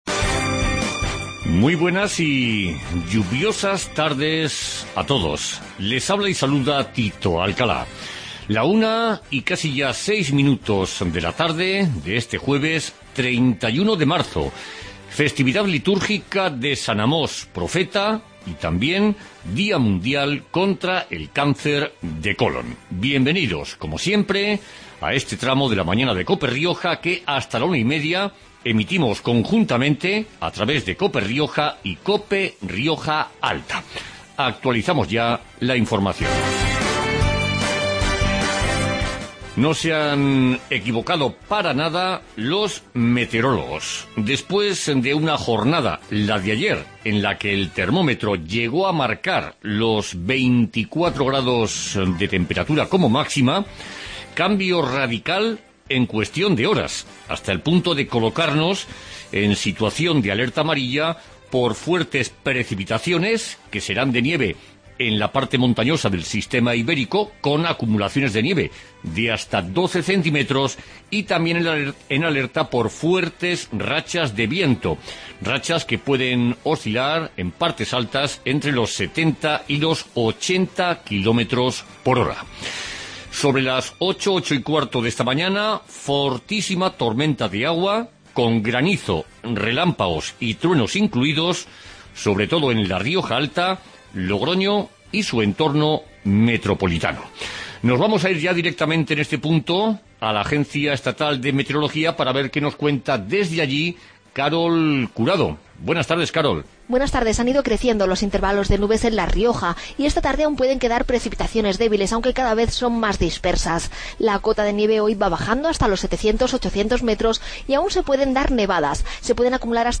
Redacción digital Madrid - Publicado el 31 mar 2016, 14:50 - Actualizado 18 mar 2023, 16:55 1 min lectura Descargar Facebook Twitter Whatsapp Telegram Enviar por email Copiar enlace Magazine de actualidad riojana